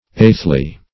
eighthly - definition of eighthly - synonyms, pronunciation, spelling from Free Dictionary Search Result for " eighthly" : The Collaborative International Dictionary of English v.0.48: Eighthly \Eighth"ly\, adv. As the eighth in order.